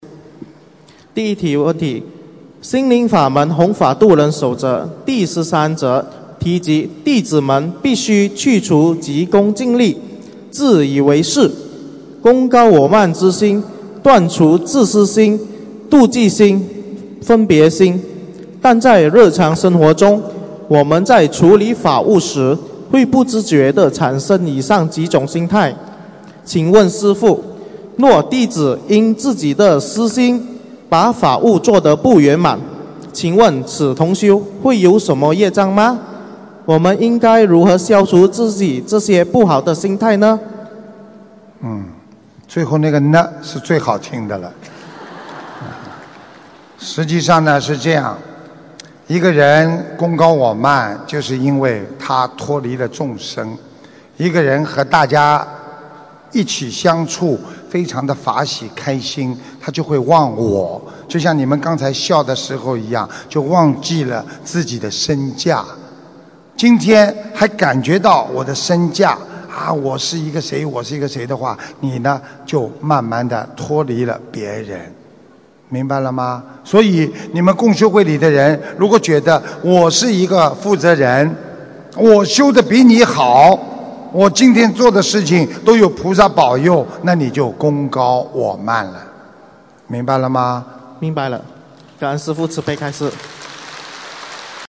如何判断自己是否贡高我慢┃弟子提问 师父回答 - 2017 - 心如菩提 - Powered by Discuz!